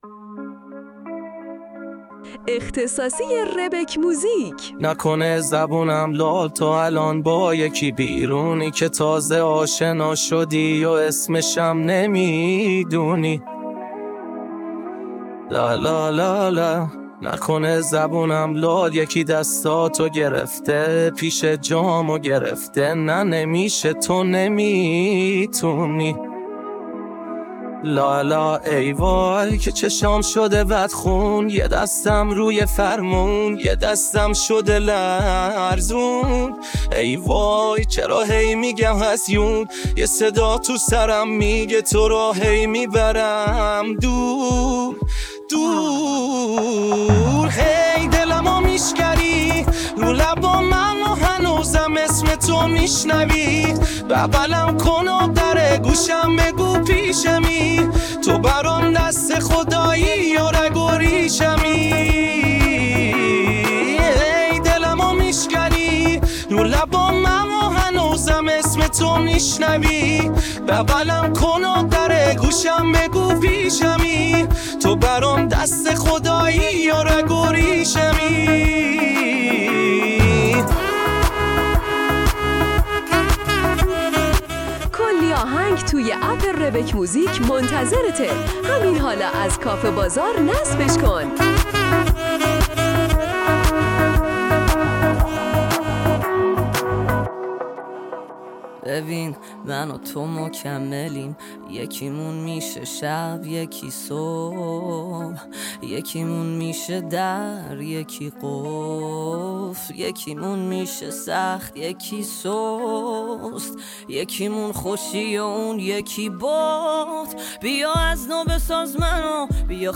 آهنگ هوش مصنوعی